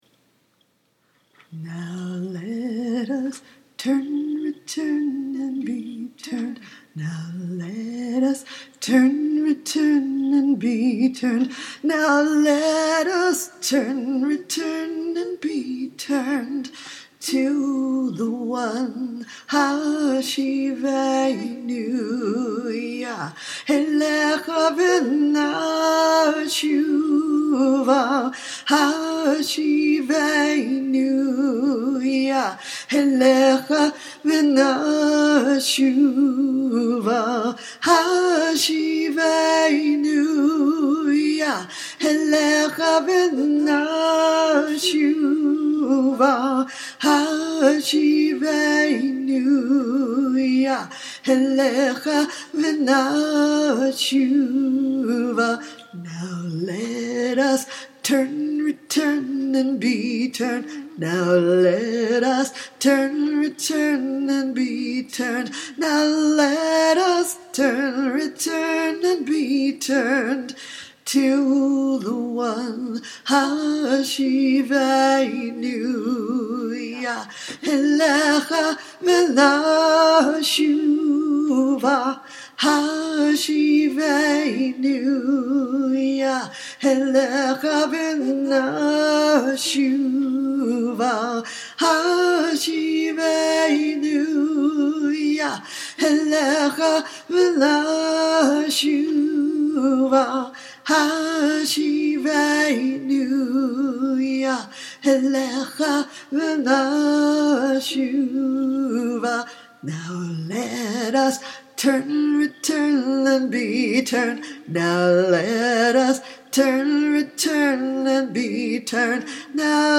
Chants, Scripture